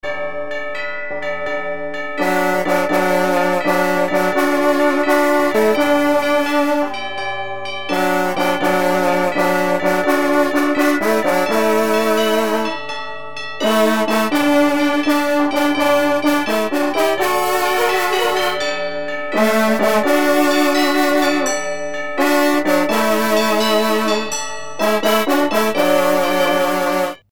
dans tous les enregistrements, le fond sonore est sur le canal gauche, la partie à travailler sur le canal droit !
Partie_F_seconde_canal_droit.mp3